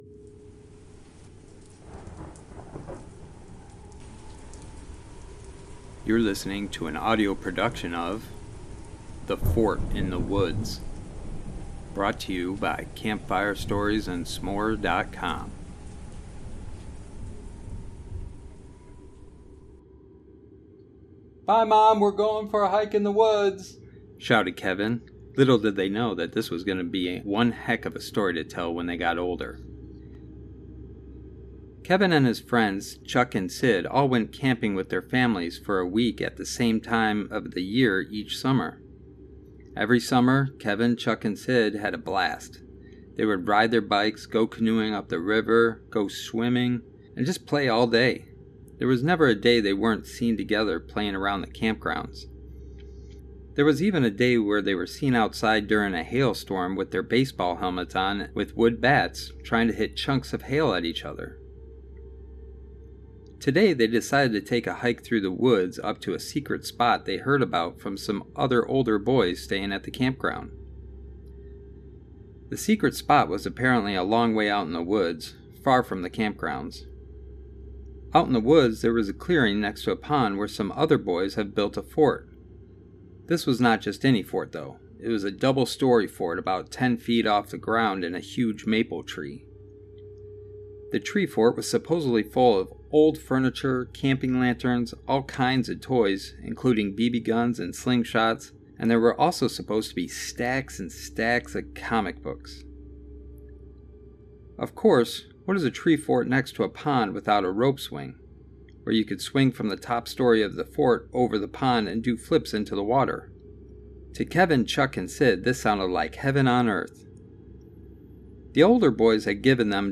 The Fort In The Woods (With Full Audio Version) is a scary story of three boys in search for a Fort in the Woods only later find out what they were truly in for